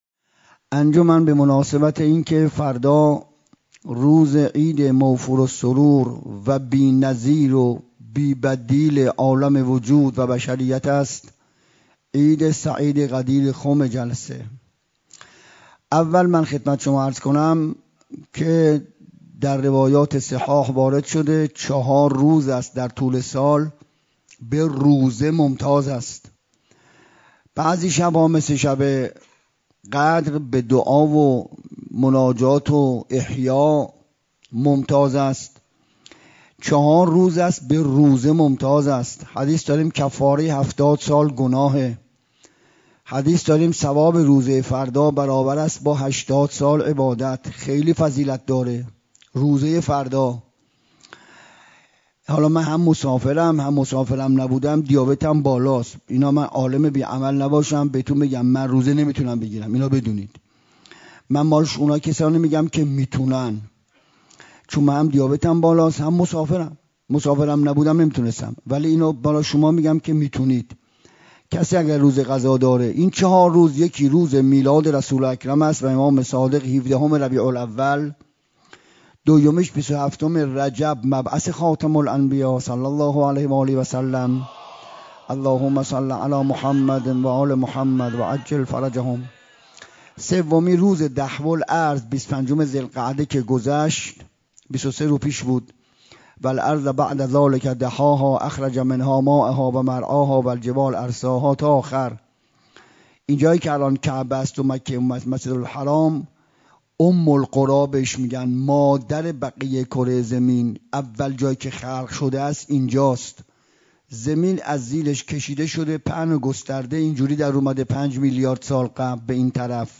7 شهریور 97 - حسینیه بیت العباس - سخنرانی